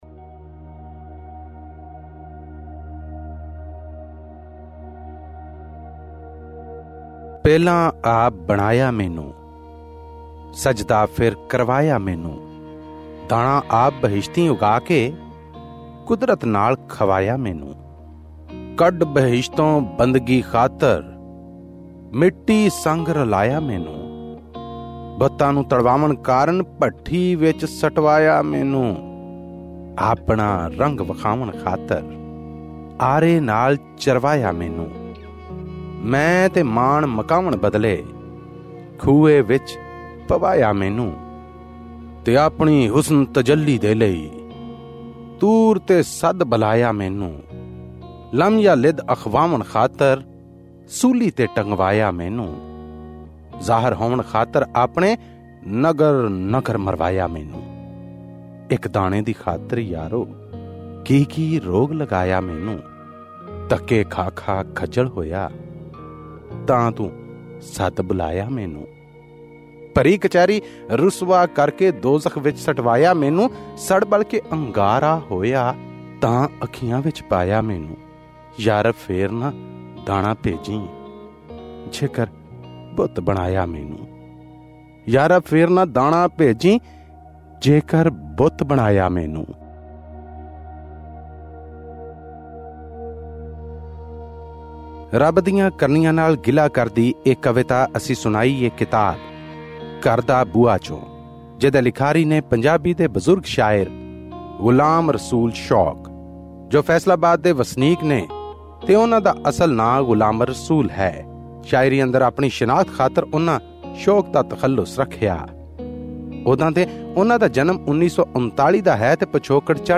Pakistani Punjabi poetry book review: 'Ghar Da Buha' by Ghulam Rasool Shouq